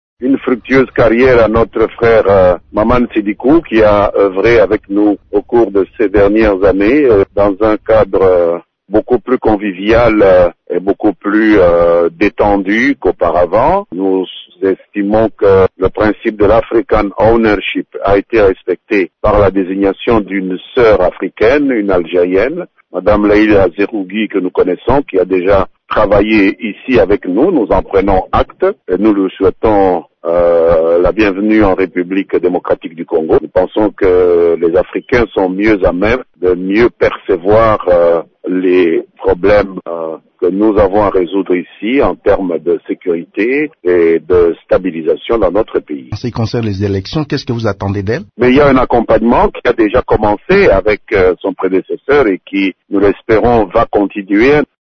«Nous pensons que les Africains sont mieux à même de percevoir les problèmes que nous avons à résoudre ici, en termes de sécurité et de stabilisation dans notre pays », a affirmé vendredi 29 décembre Lambert Mende, dans une interview accordée à Radio Okapi.